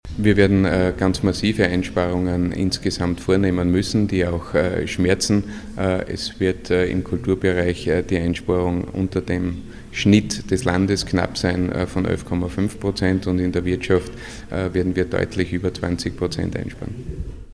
O-Töne Landesrat Christian Buchmann: